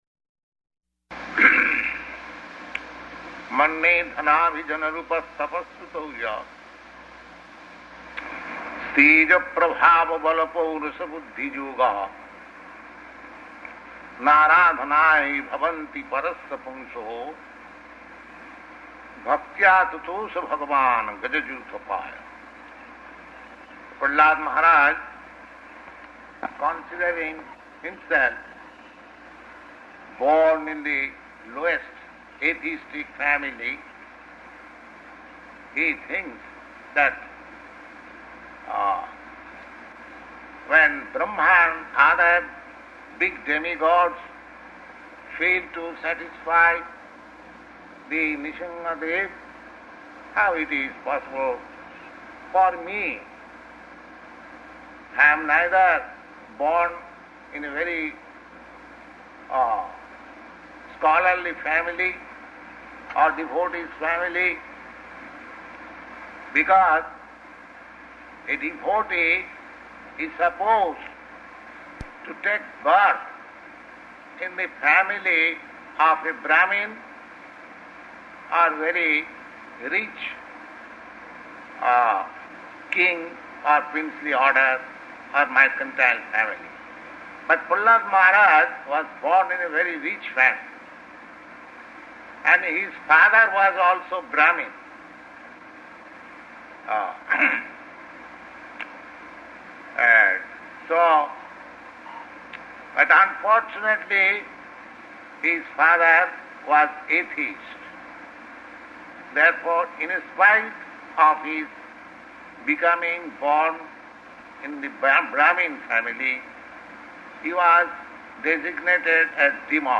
Type: Srimad-Bhagavatam
Location: Montreal